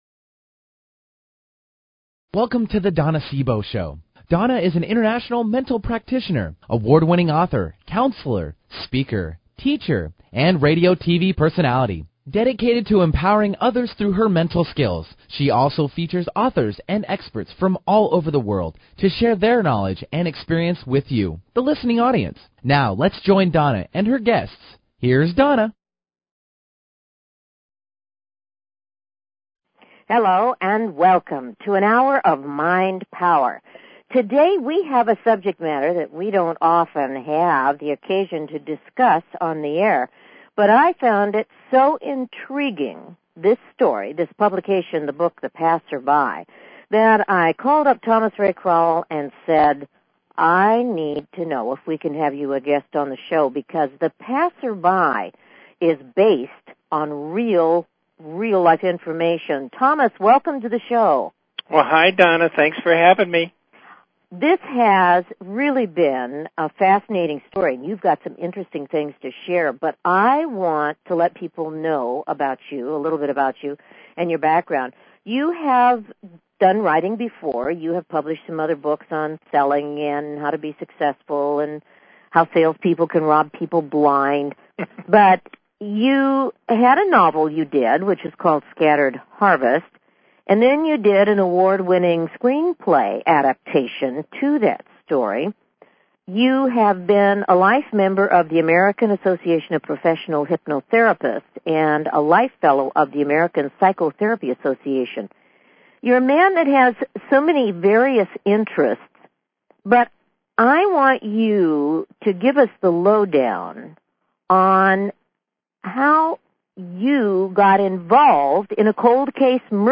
Talk Show Episode
Guests on her programs include CEO's of Fortune 500 companies to working mothers.